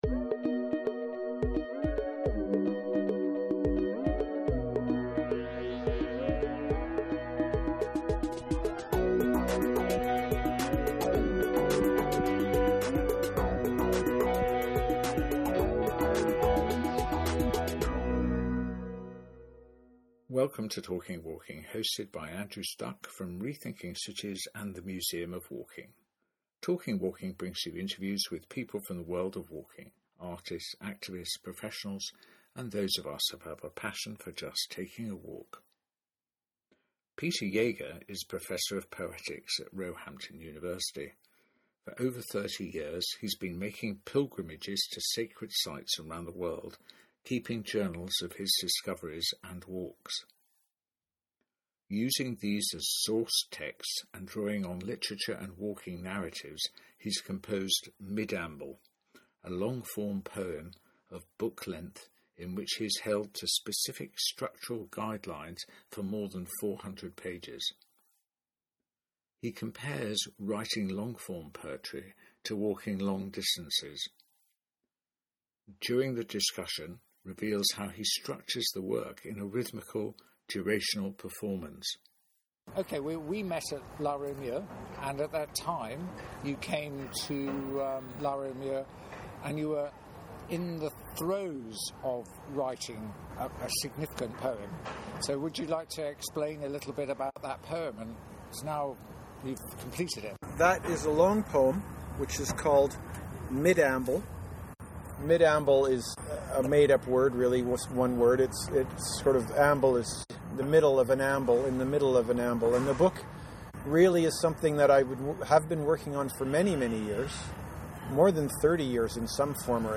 Interview with authors